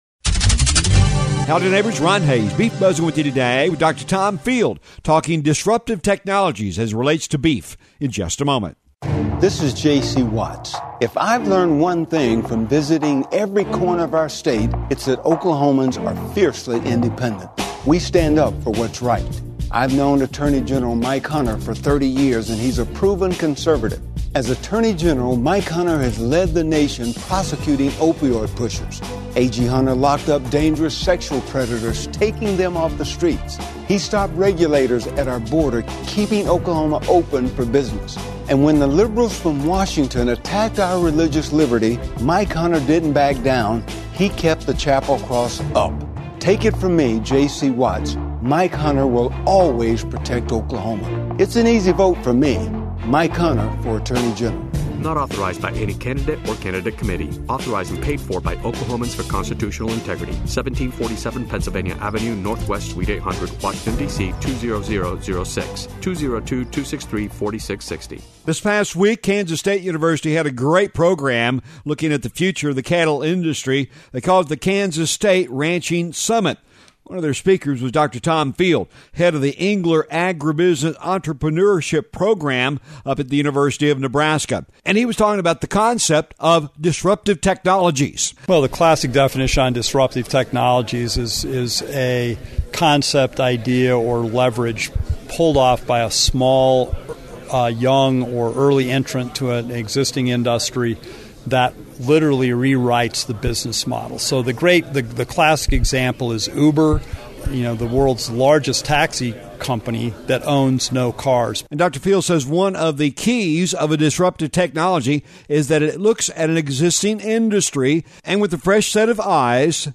The Beef Buzz is a regular feature heard on radio stations around the region on the Radio Oklahoma Network and is a regular audio feature found on this website as well.